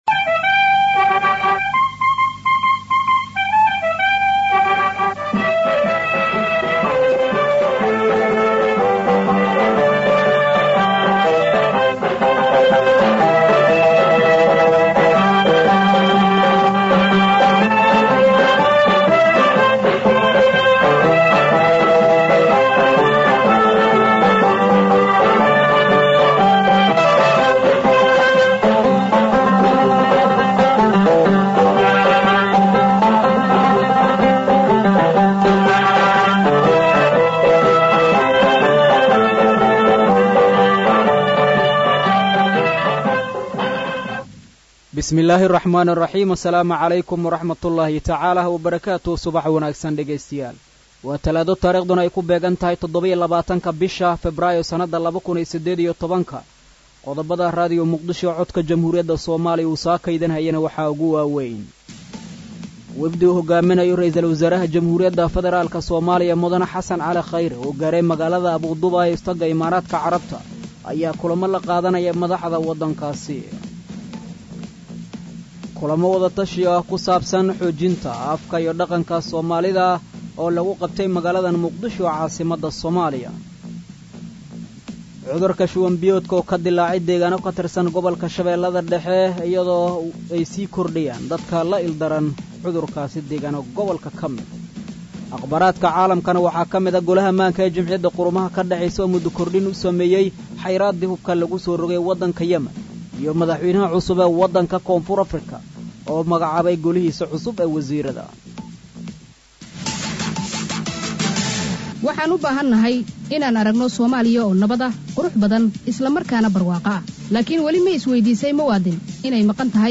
Dhageyso: Warka Subax ee Radio Muqdisho.